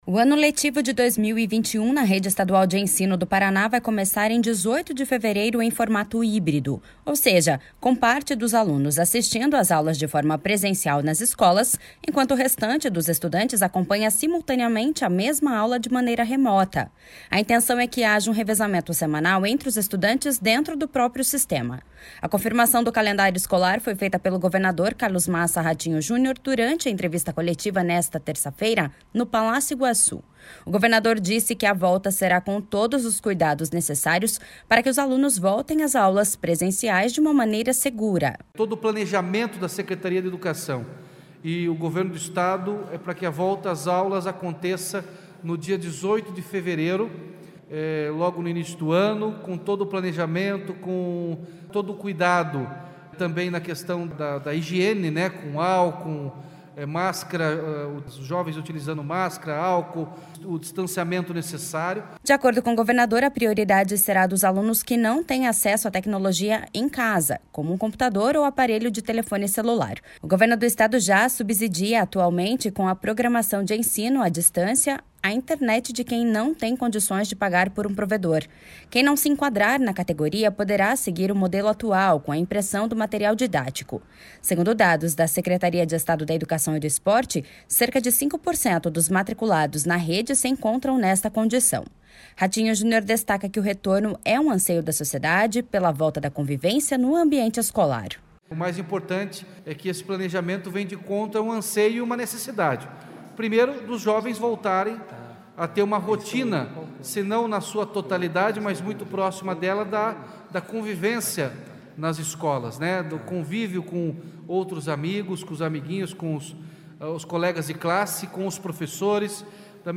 A confirmação do calendário escolar foi feita pelo governador Carlos Massa Ratinho Junior durante entrevista coletiva, nesta terça-feira, no Palácio Iguaçu. O governador disse que a volta será com todos os cuidados necessários para que os alunos voltem às aulas presenciais de uma maneira segura.// SONORA RATINHO JUNIOR.//
Os colégios estaduais vão adotar as medidas do protocolo de prevenção contra a Covid-19 elaborado pela Secretaria de Estado da Saúde, seguindo o mesmo modelo já usado no retorno de atividades extracurriculares no fim de outubro. O secretário estadual da Educação, Renato Feder, explica que haverá medição de temperatura na entrada das escolas, uso obrigatório de máscara e distanciamento social.// SONORA RENATO FEDER.//